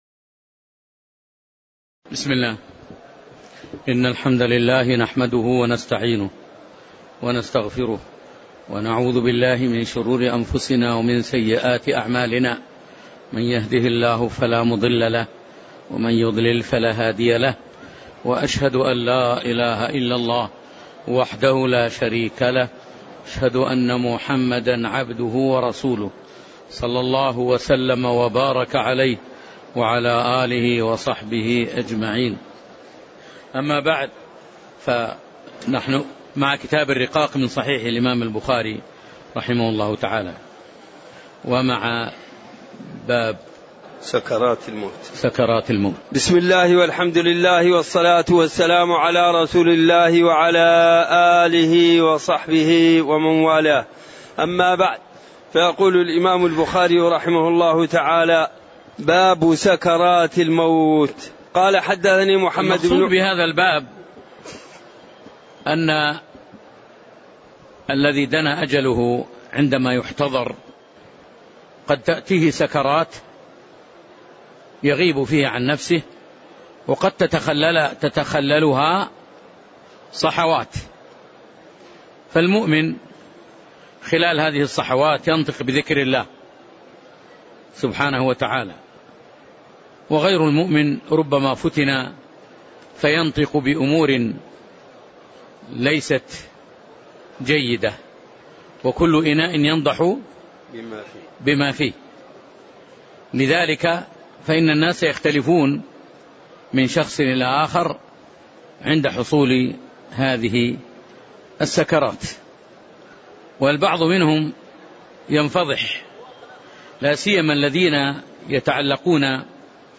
تاريخ النشر ١٩ رمضان ١٤٣٩ هـ المكان: المسجد النبوي الشيخ